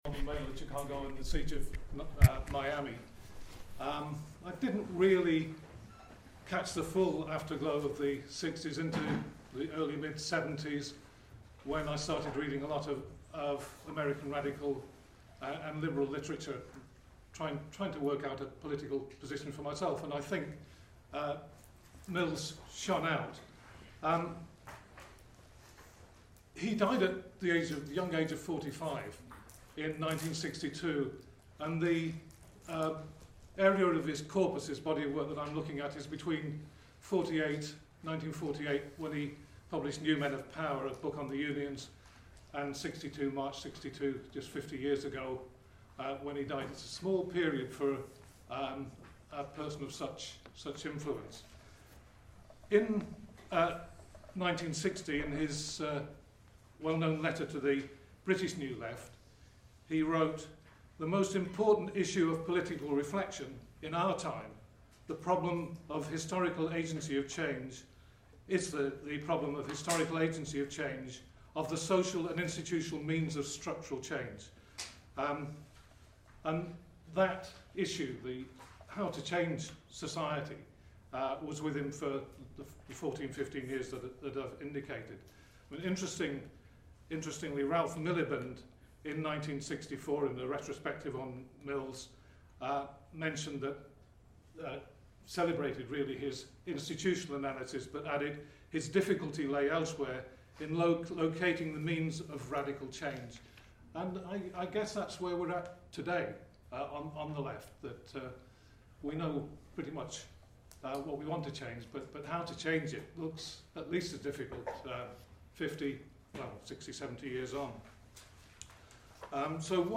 talk
Problem of Radical Agency” from the C Wright Mills session I organised at the BSA conference in Leeds.